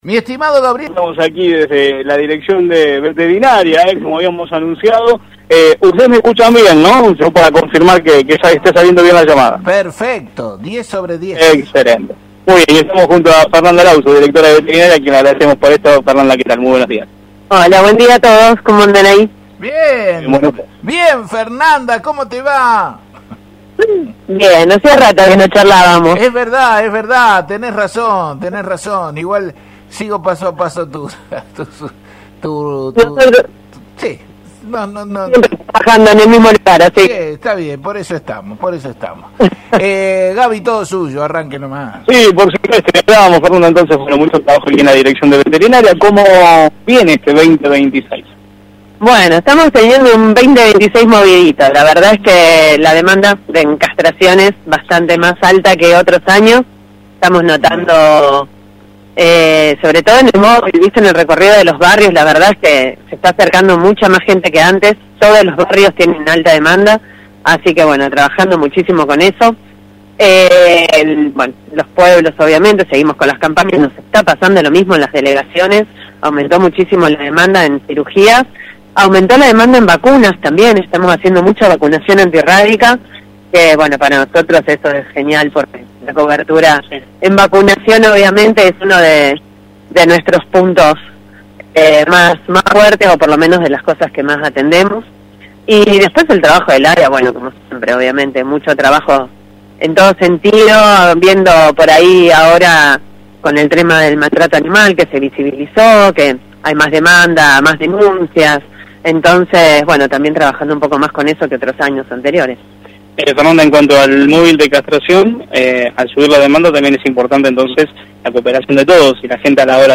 En el marco del móvil de Radio Mon AM 1540